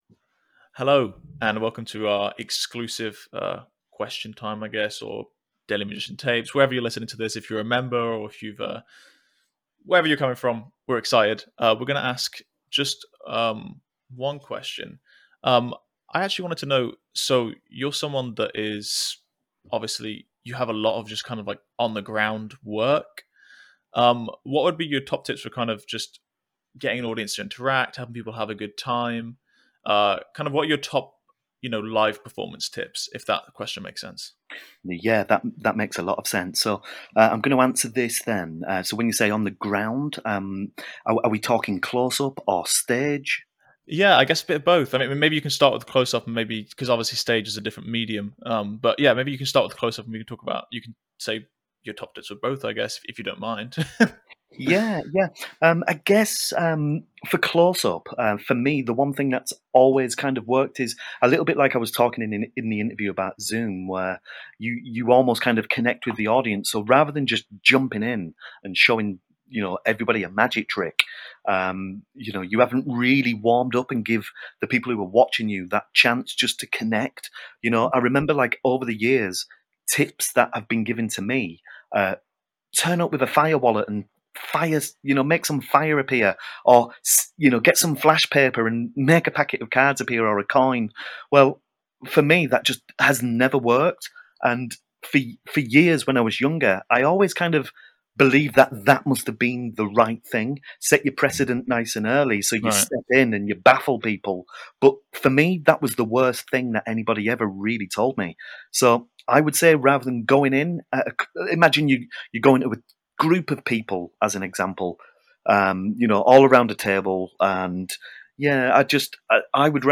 …nearly every time we’ve interviewed a magician on our podcast, we’ve recorded a ‘bonus’ session that goes deep into concepts and secrets that we didn’t share on the main recording.